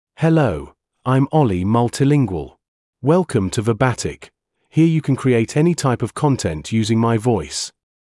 Ollie MultilingualMale English AI voice
Ollie Multilingual is a male AI voice for English (United Kingdom).
Voice sample
Listen to Ollie Multilingual's male English voice.
Male
Ollie Multilingual delivers clear pronunciation with authentic United Kingdom English intonation, making your content sound professionally produced.